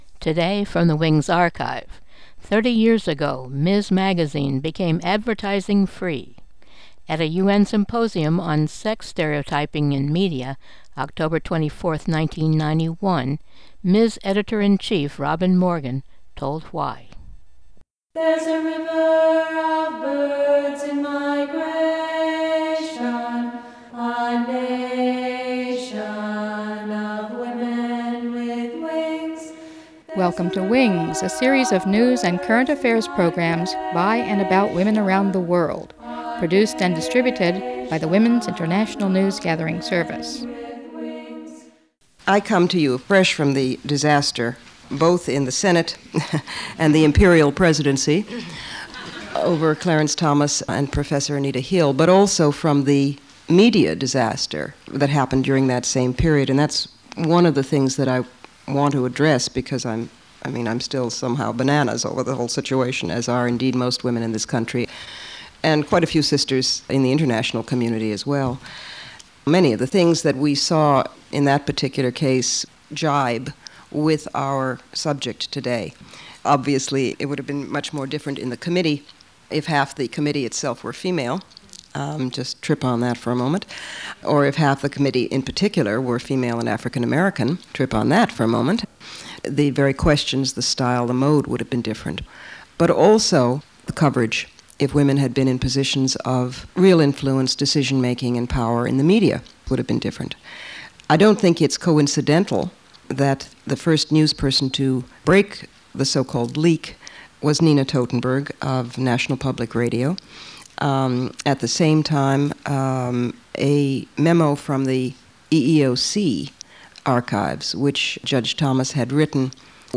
Talk by Robin Morgan at UN Symposium on Sex Stereotyping In Media